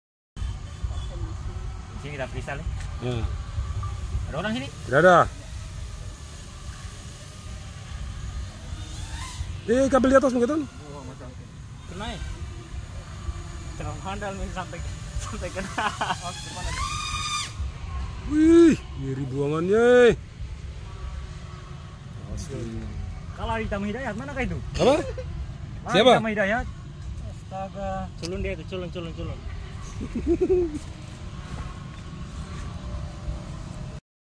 EDISI PANASI MOTOR DRONE Sound Effects Free Download